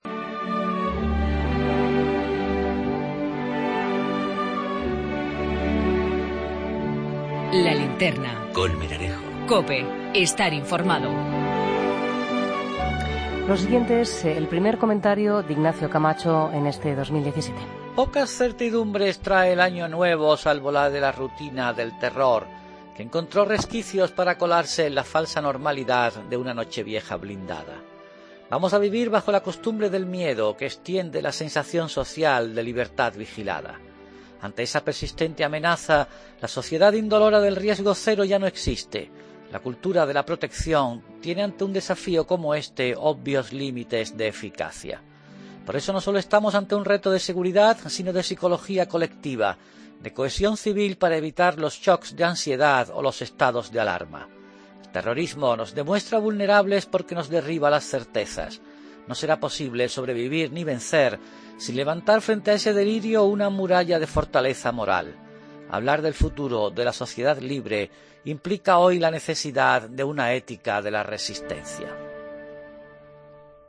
AUDIO: El comentario de Ignacio Camacho en 'La Linterna' tras el ataque en una discoteca de Estambul en Nochevieja